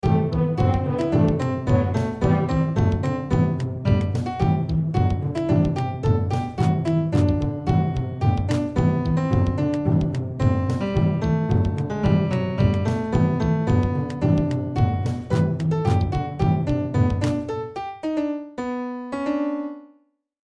- Piano Music, Solo Keyboard - Young Composers Music Forum